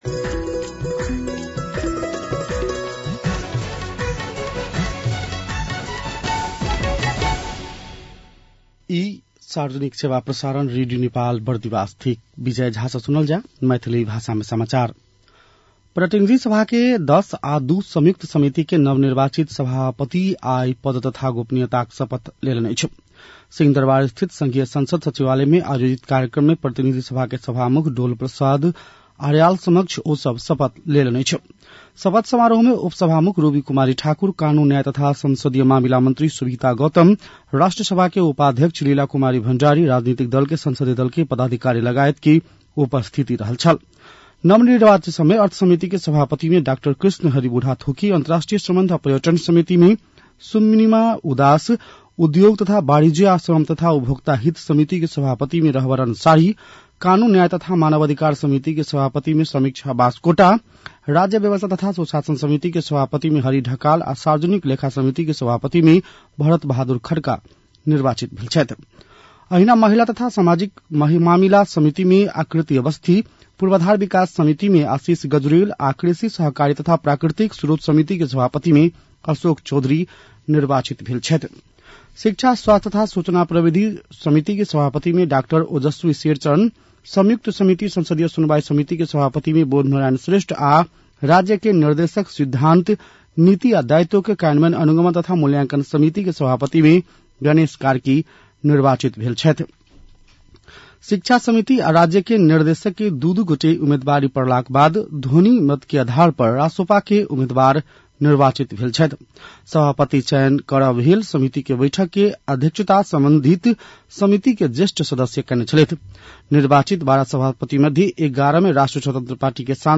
मैथिली भाषामा समाचार : ४ वैशाख , २०८३